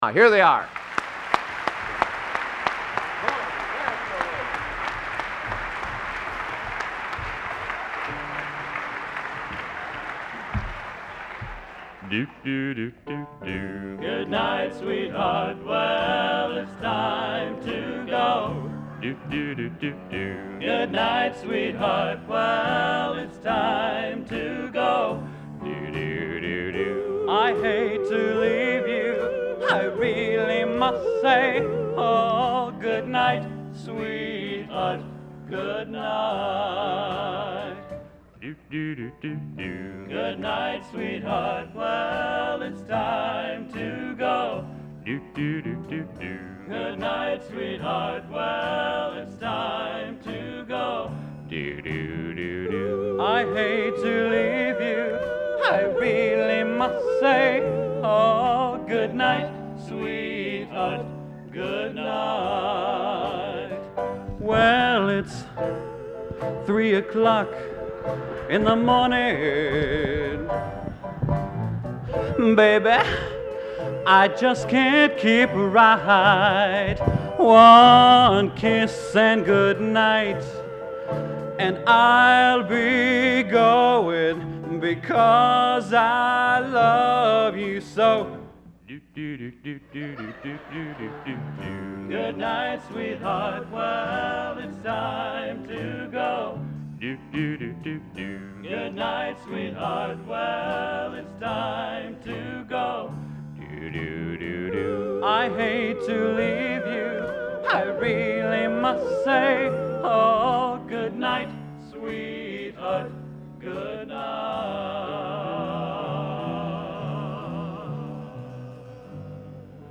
Location: West Lafayette, Indiana
Genre: Doo Wop | Type: End of Season |Specialty